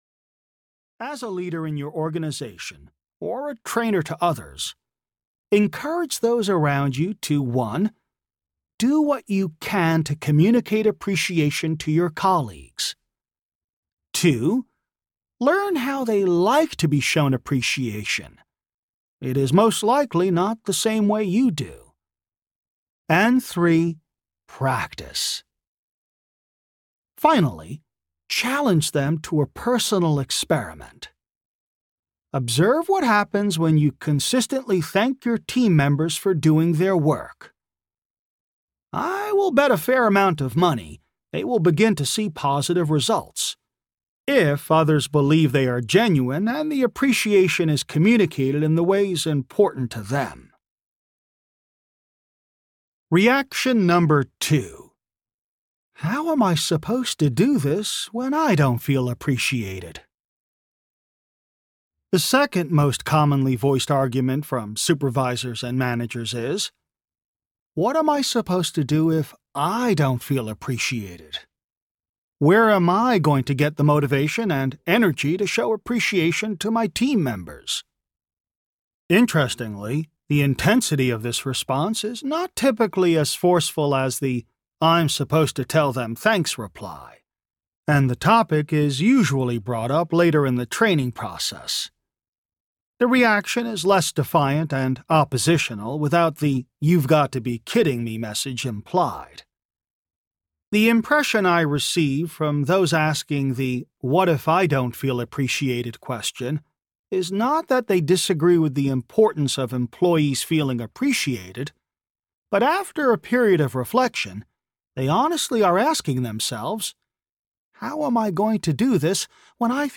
An audio book both for leaders and employees, The Vibrant Workplace explains: